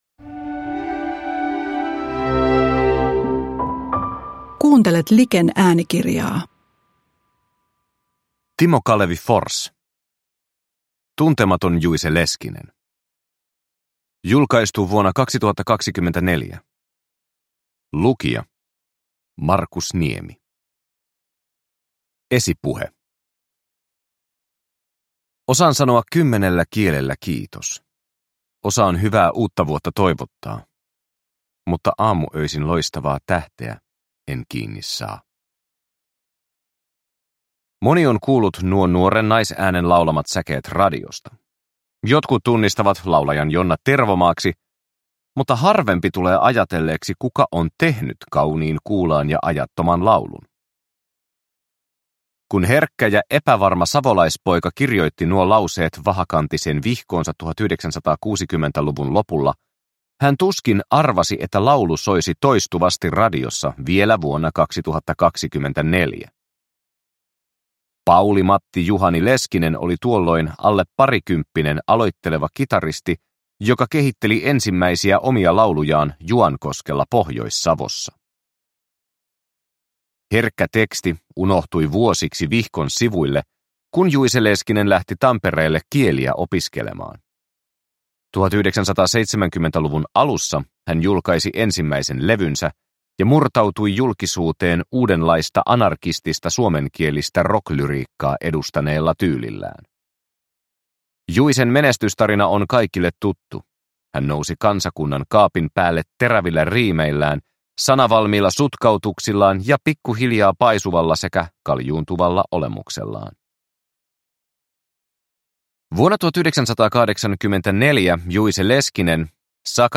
Tuntematon Juice Leskinen (ljudbok